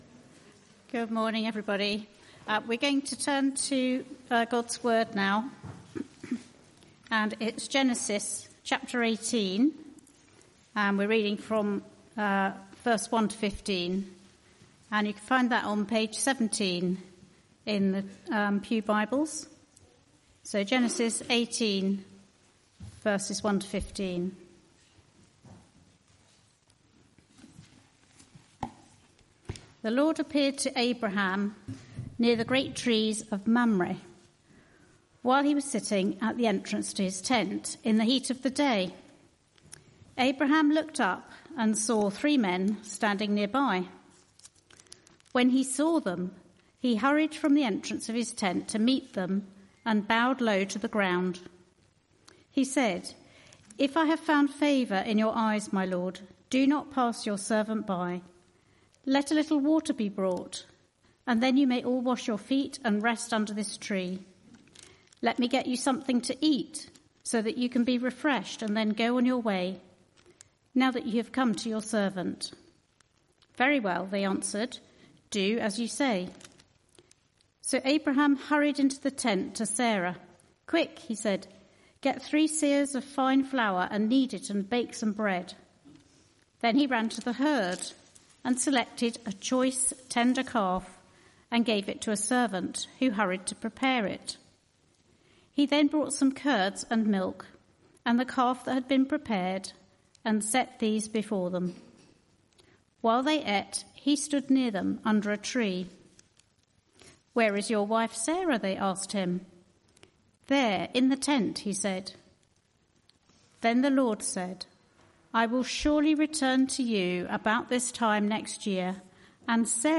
Arborfield Morning Service
Sermon